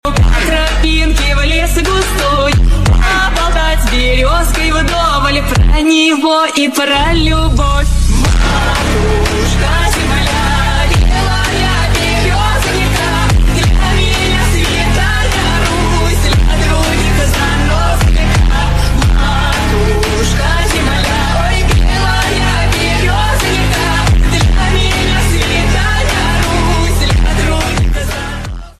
wolves caught on camera🐺 ||| sound effects free download
Wolf🔥